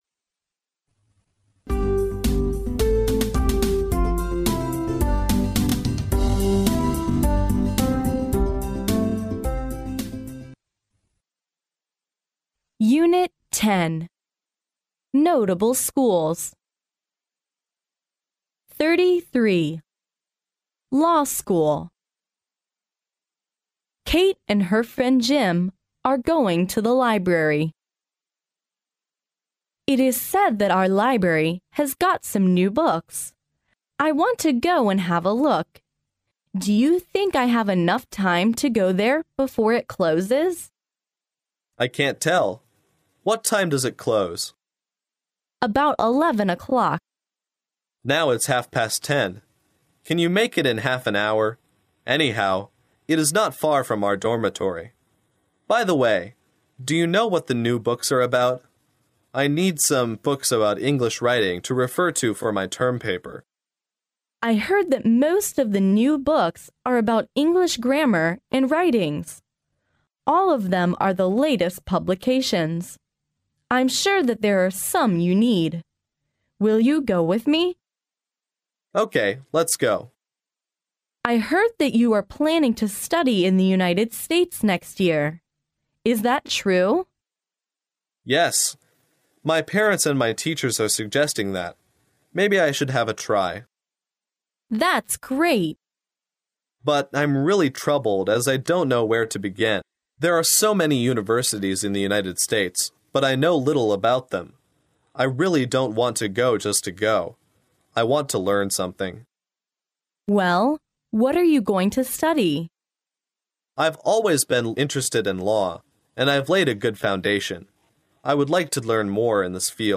耶鲁大学校园英语情景对话33：法学院（mp3+中英）